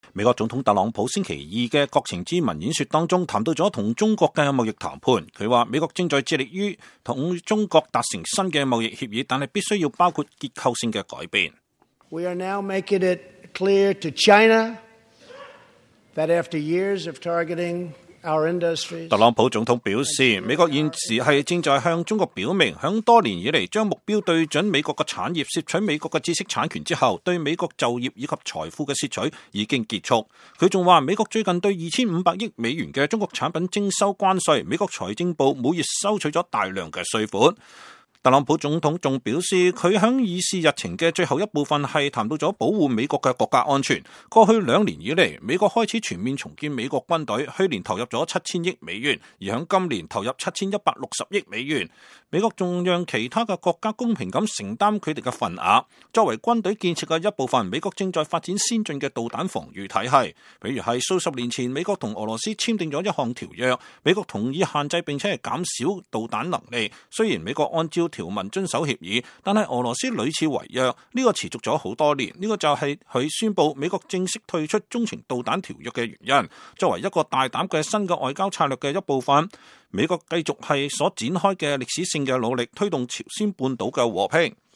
特朗普總統於國情咨文中談及中國的段落
美國總統特朗普在星期二(2月5日)的國情咨文演說中談了與中國的貿易談判。他說，美國正在致力於與中國達成新的貿易協議，但它必須包括結構性的改變。特朗普總統還希望中國等國也加入未來的新中導條約。